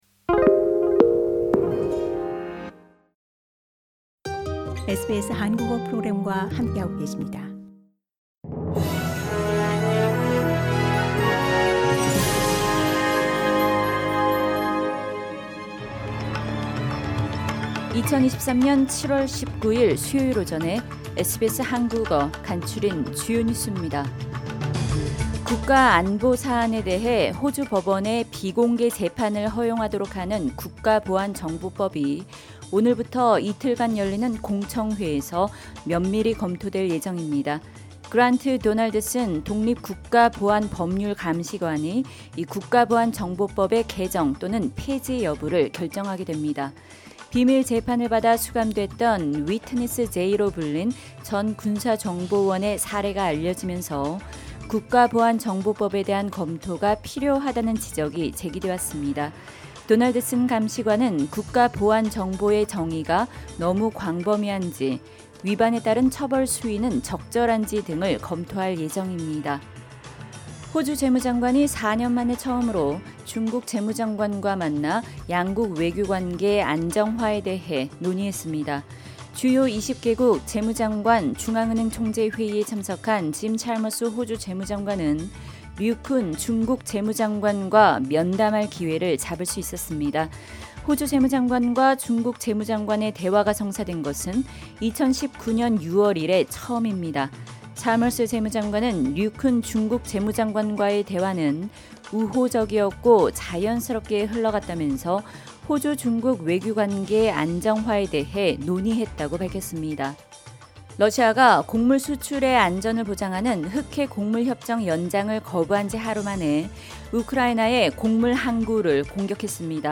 SBS 한국어 아침 뉴스: 2023년 7월19일 수요일